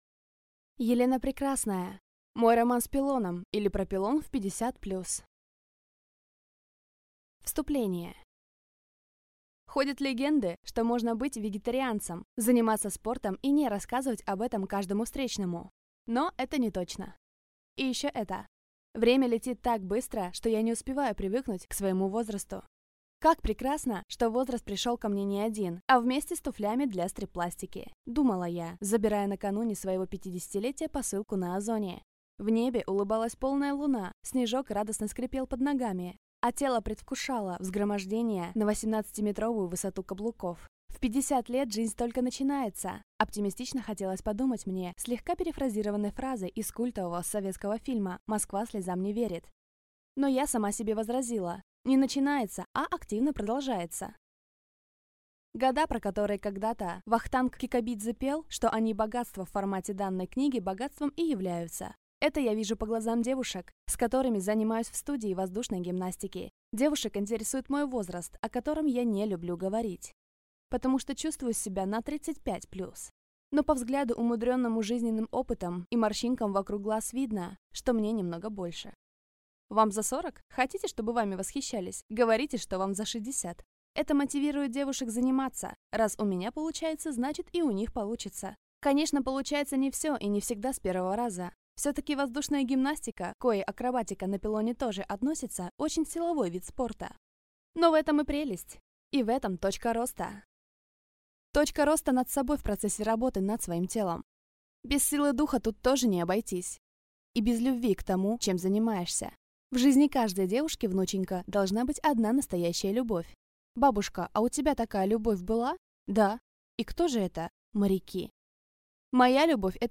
Аудиокнига Мой роман с пилоном, или Про Пилон в 50+ | Библиотека аудиокниг